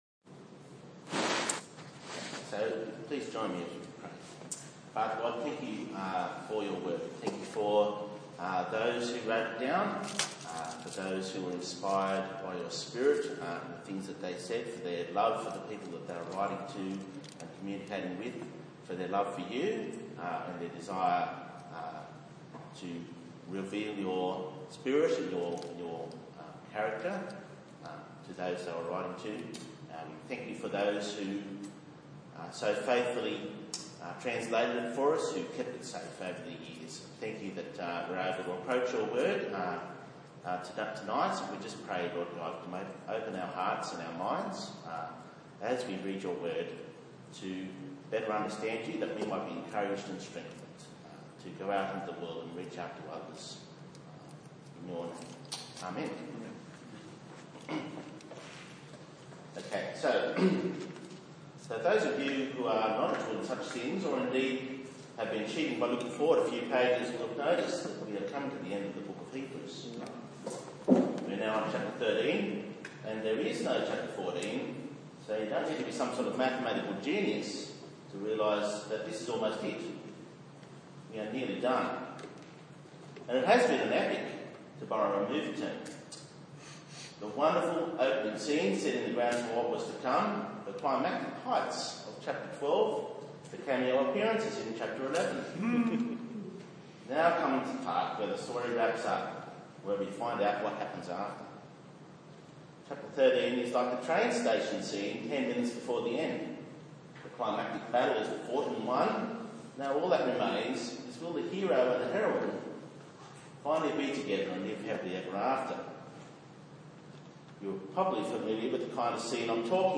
19/06/2016 The Lord is our Helper Preacher